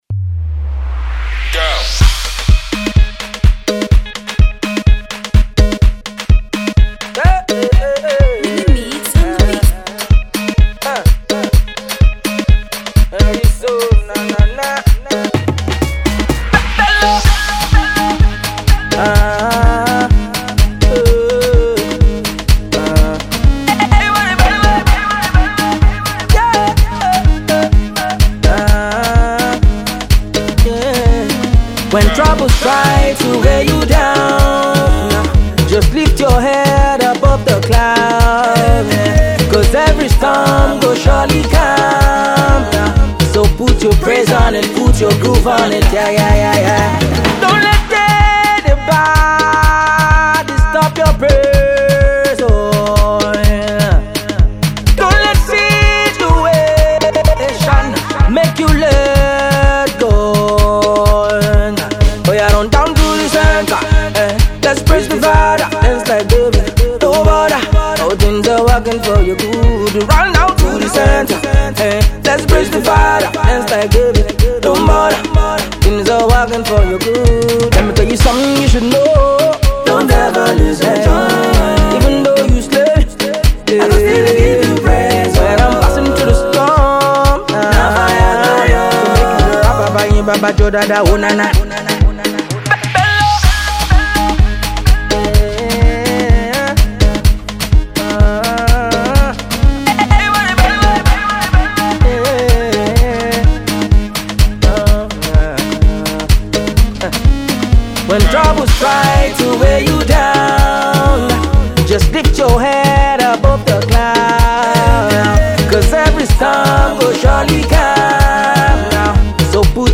Afro beat dance track